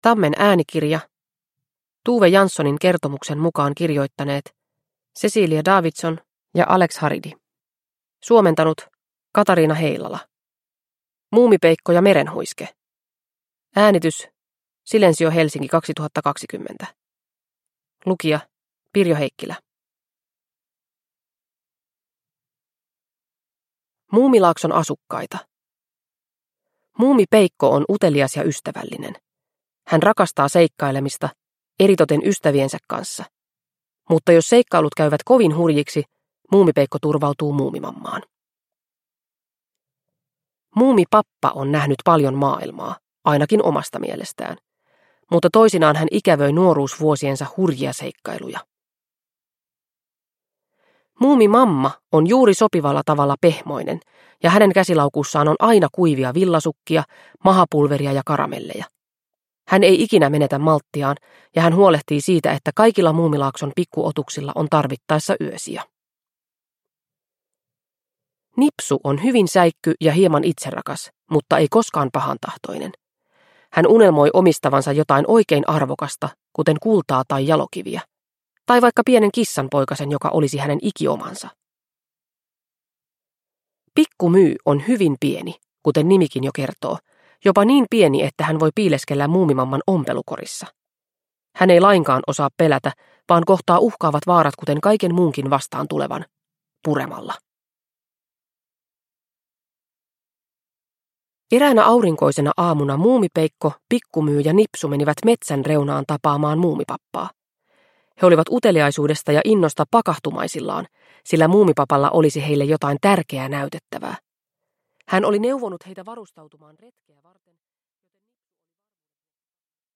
Muumipeikko ja Merenhuiske – Ljudbok – Laddas ner